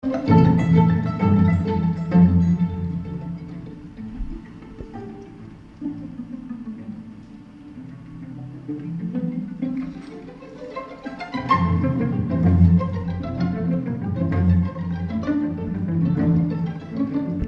pizzicato